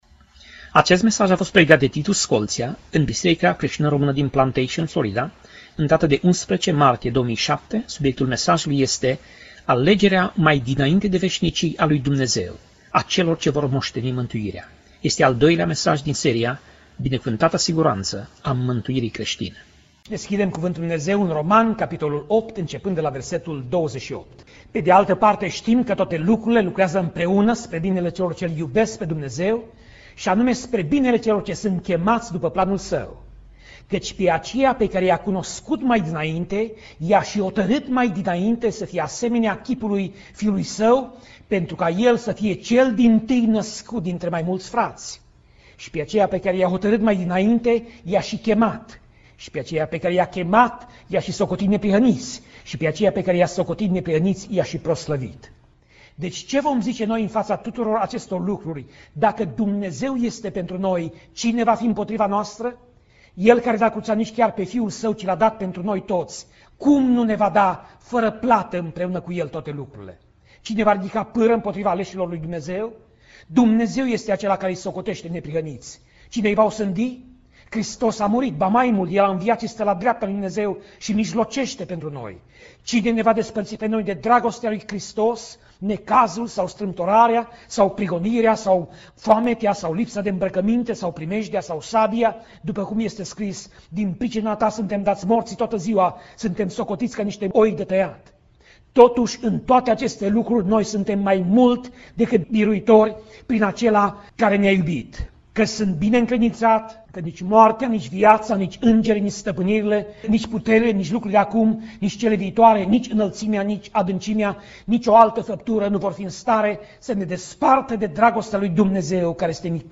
Pasaj Biblie: Romani 8:28 - Romani 8:30 Tip Mesaj: Predica